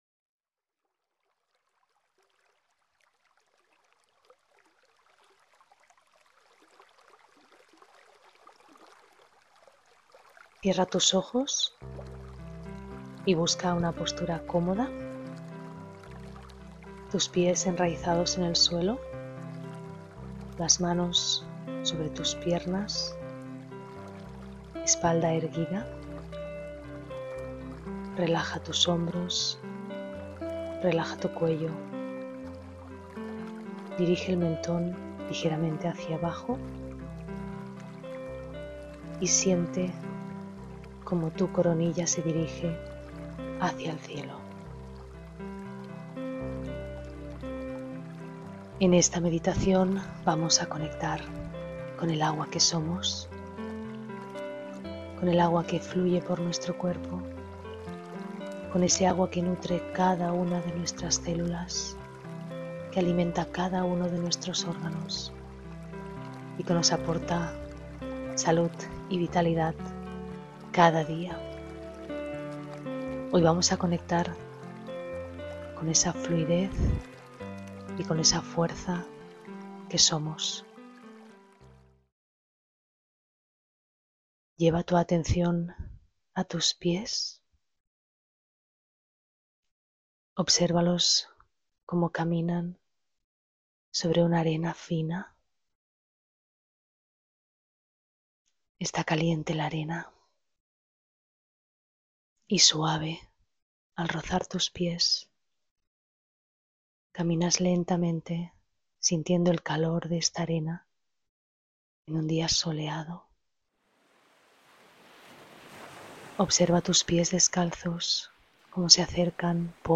ESCUCHA LA MEDITACIÓN GUIADA
Meditacion-de-Agua.mp3